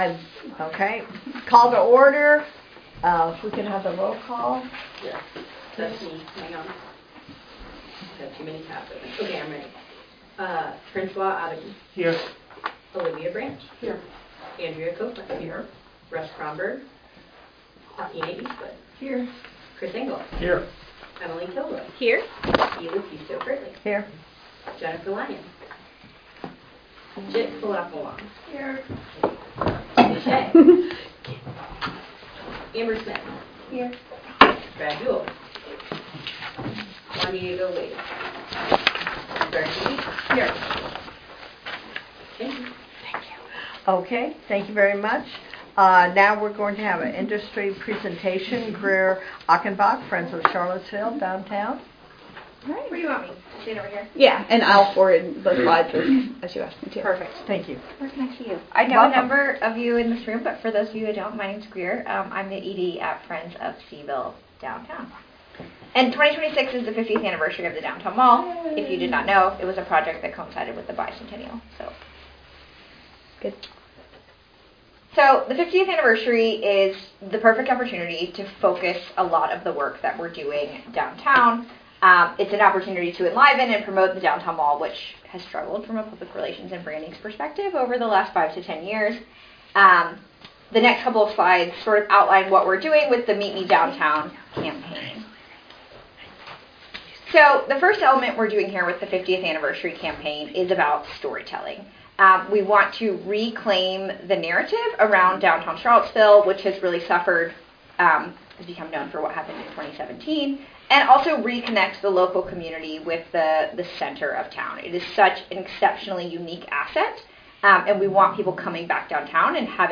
The audio recording and Board packet documents from the December 2025 CACVB Board meeting can be accessed here: